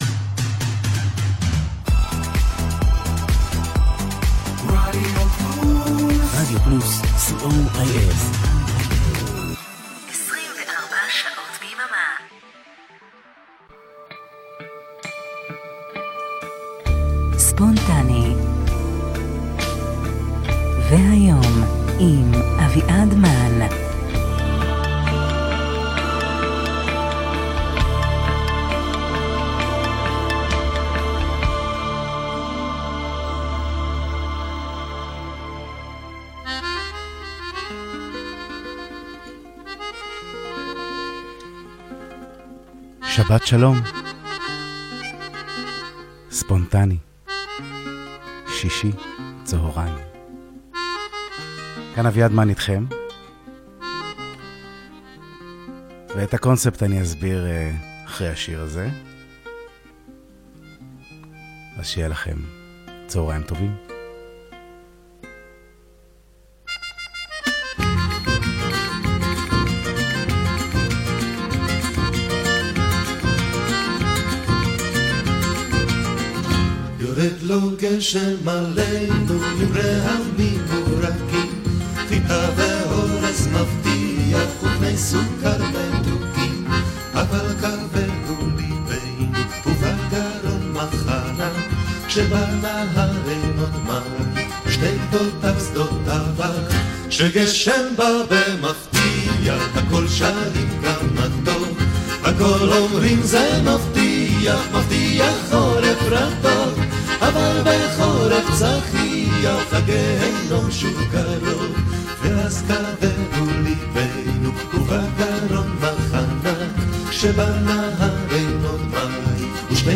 תוכנית עברית רגועה עם שירים שקצת נשכחו מלב… שבת שלום! 🙂